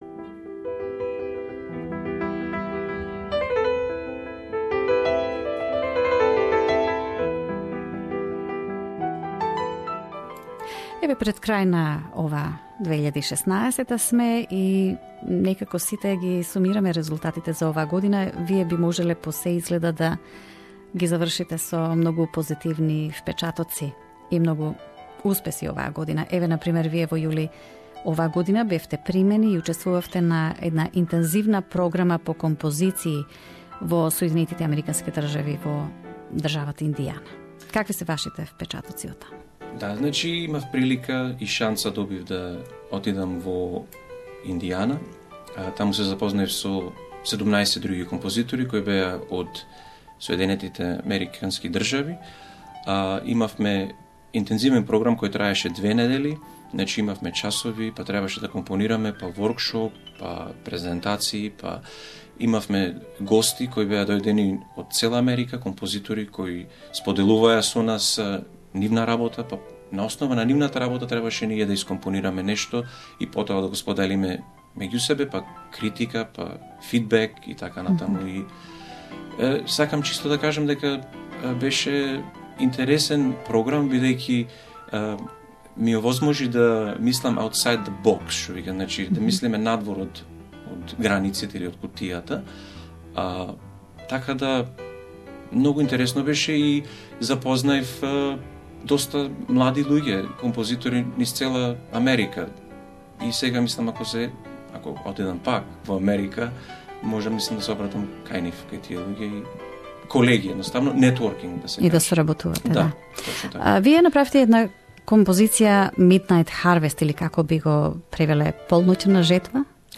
composer at SBS Studios Source